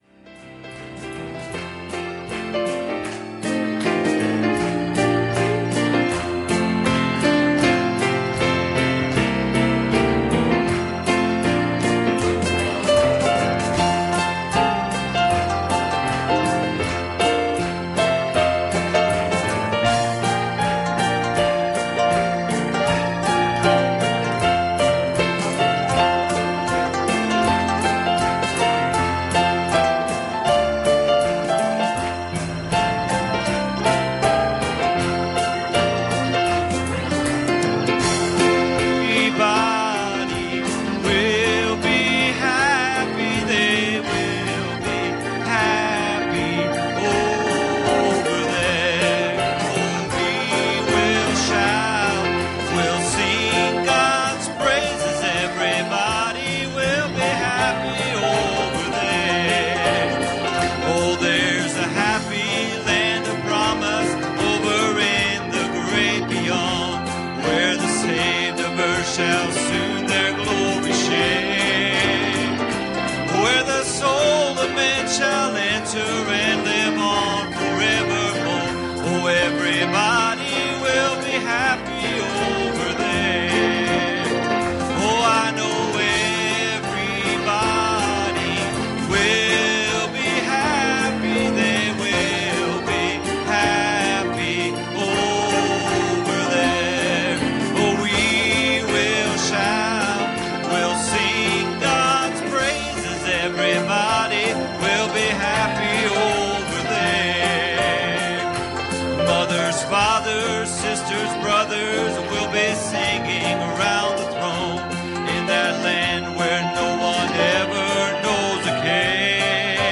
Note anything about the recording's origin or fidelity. Passage: Matthew 13:45 Service Type: Youth Service